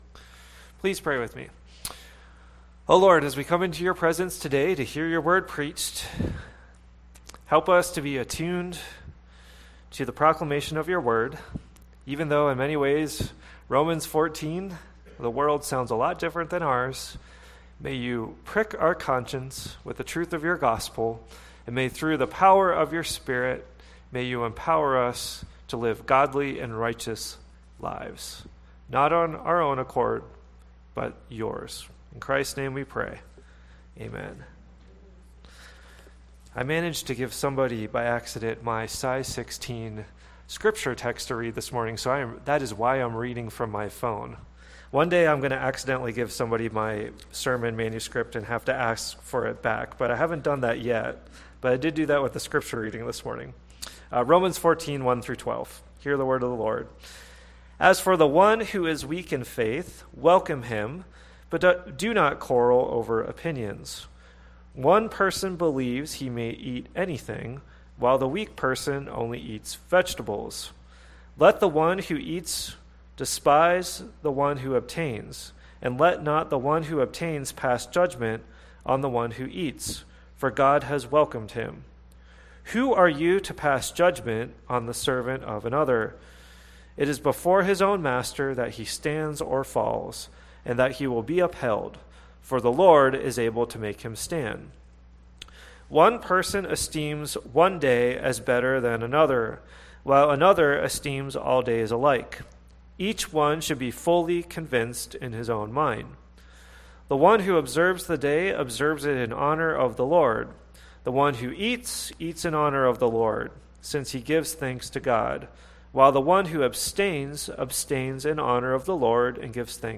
Worship bulletin: October 29, 2023 New Testament reading: Matthew 7:1-6 Sermon text: Romans 14:1-12 Sermon: “The Strong and the Weak” Audio (MP3) 14 MB Previous Do We Really Believe the Gospel?